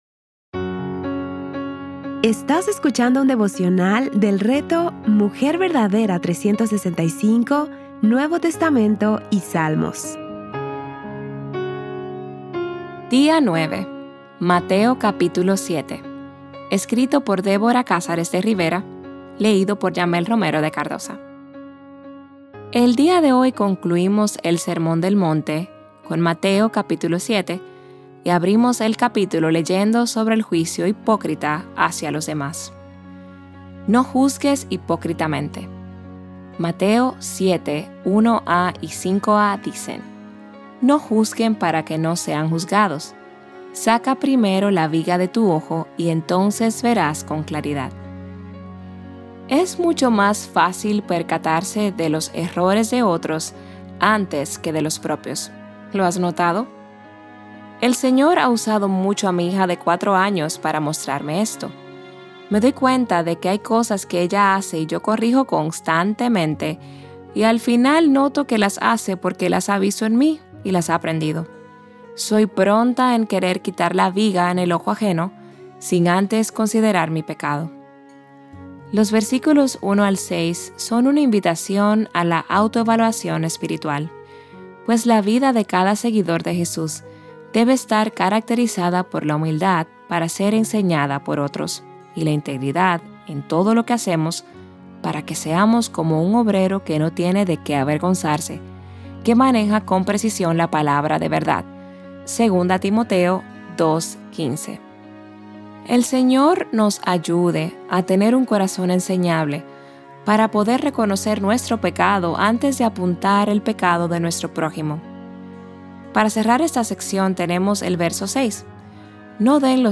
Series:  Mateo y Salmos | Temas: Lectura Bíblica